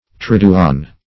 Meaning of triduan. triduan synonyms, pronunciation, spelling and more from Free Dictionary.
Search Result for " triduan" : The Collaborative International Dictionary of English v.0.48: Triduan \Trid"u*an\, a. [L. triduanus, fr. triduum space of three days; tri- + dies day.]